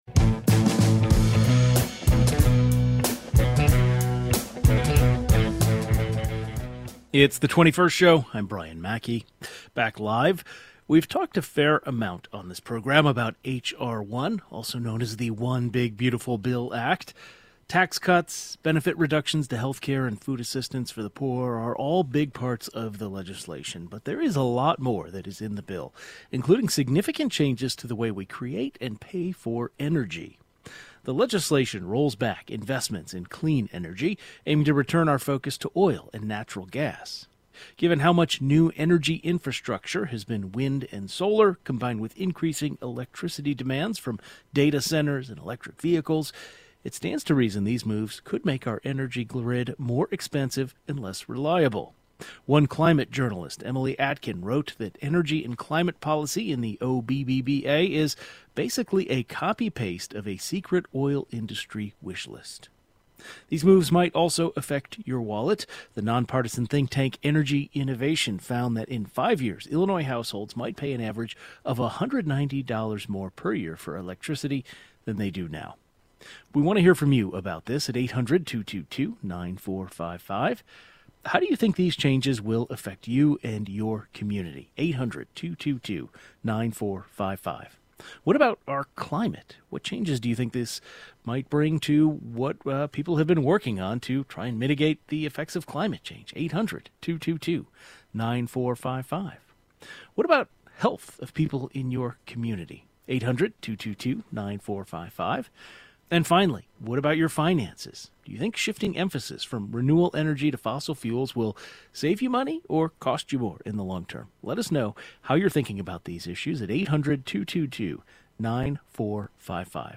The head of an Illinois-based environmental organization weighs in.